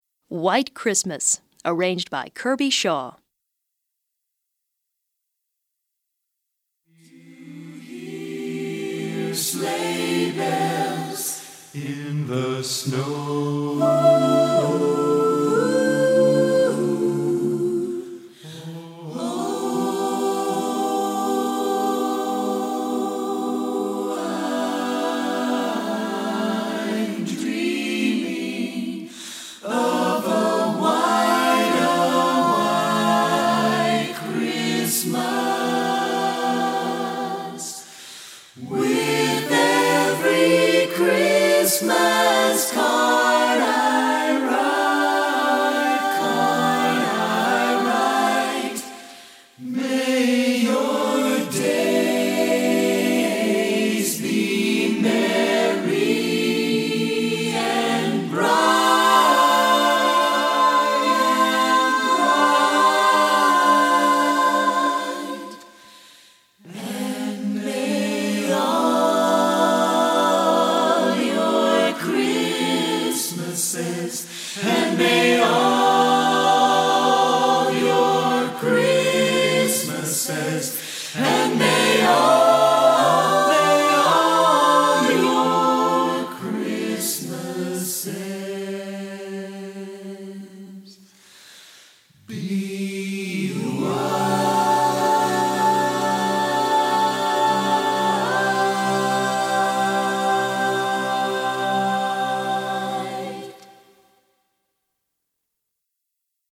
Chant Mixtes